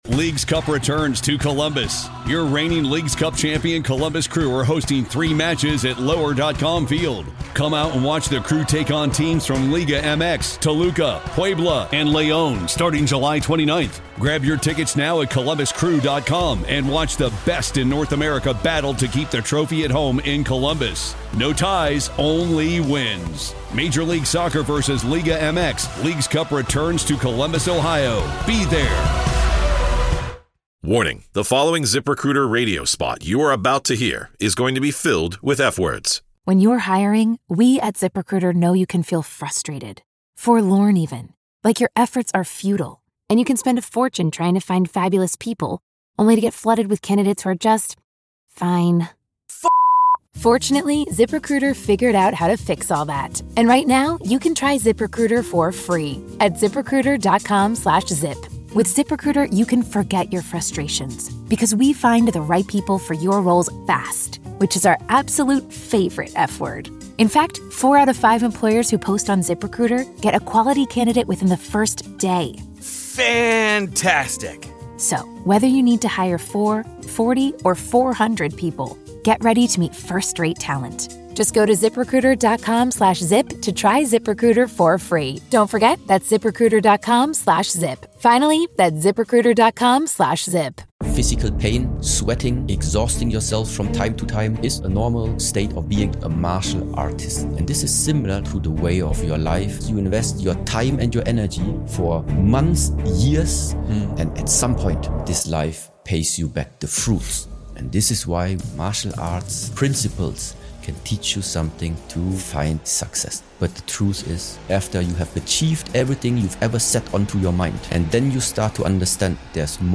We unpack what it really means to live with presence, power, and purpose and how to stop letting your emotions, distractions, and past pain run the show. From overcoming addiction to comfort to understanding why we are the cause of our suffering, this conversation is a blueprint for those ready to step out of autopilot and take their mind back.